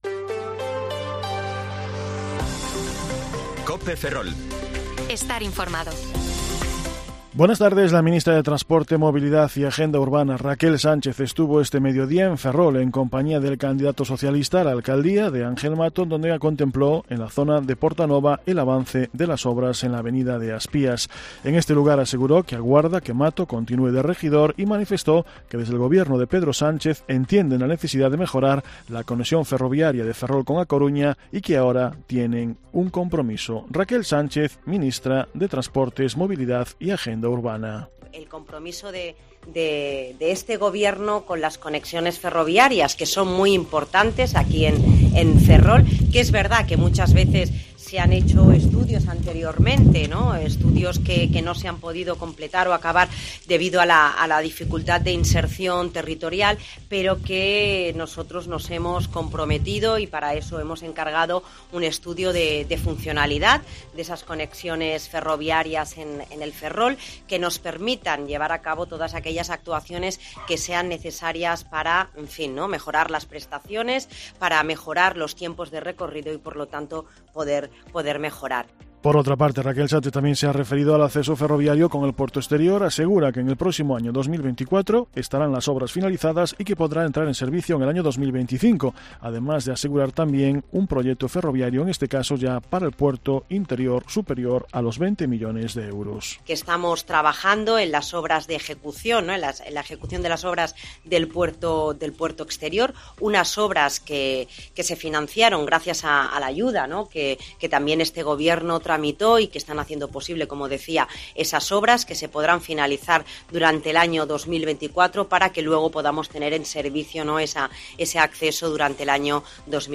Informativo Mediodía COPE Ferrol 18/05/2023 (De 14,20 a 14,30 horas)